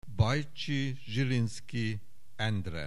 Aussprache Aussprache